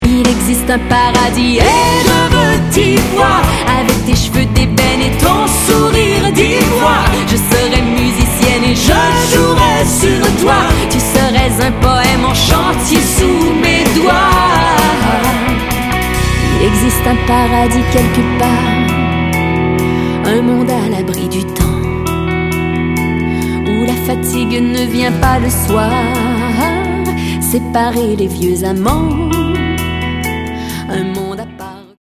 batterie
violoncelle
guitare acoustique